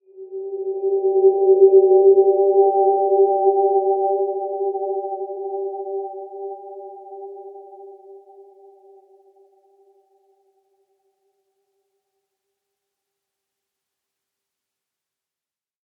Dreamy-Fifths-G4-p.wav